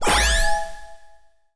sci-fi_power_up_10.wav